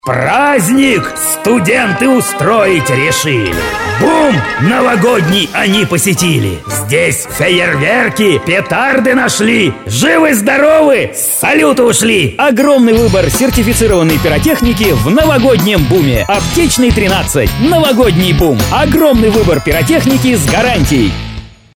Серия аудиороликов на открытие магазина "Новогодний Бум". Ролик в духе детских рифмованных страшилок.